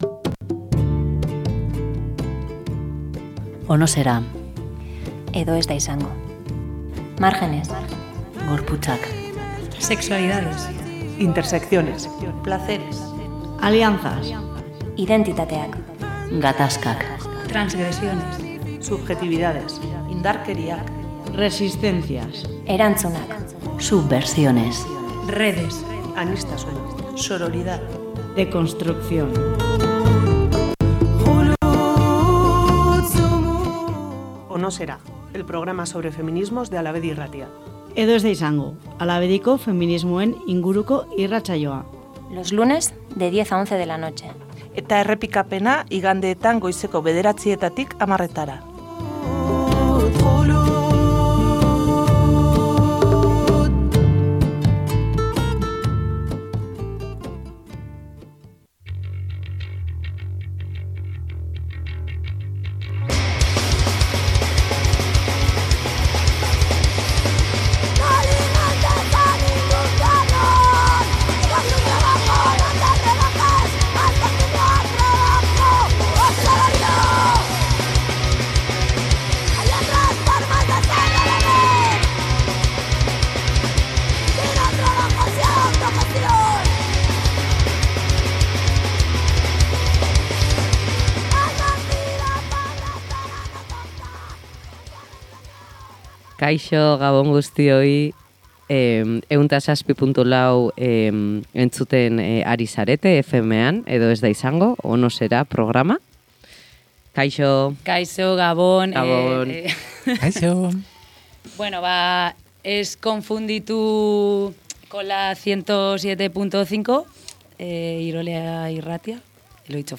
En esta ocasión han estado en los estudios de Hala Bedi